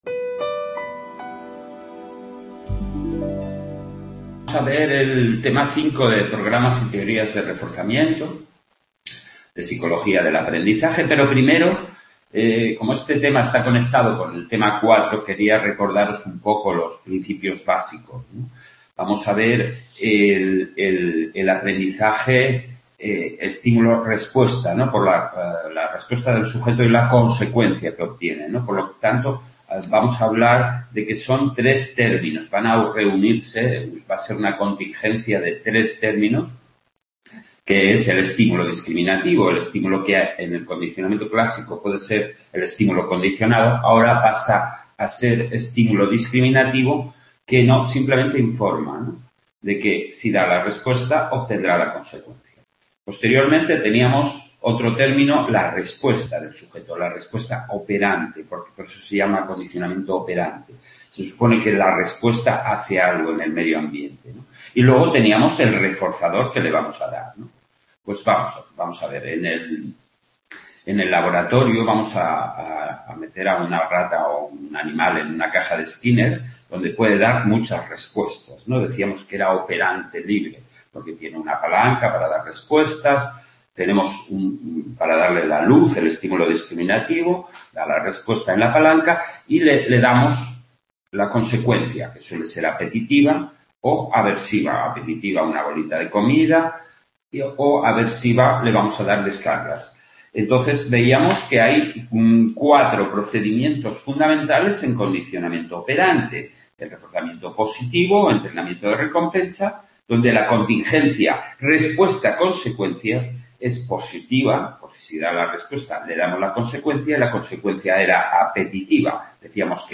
Psicología del Aprendizaje, Tema 5: Programas y Teorías de reforzamiento, parte 1. Grabación realizada en el C.A.. de Sant Boi